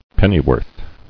[pen·ny·worth]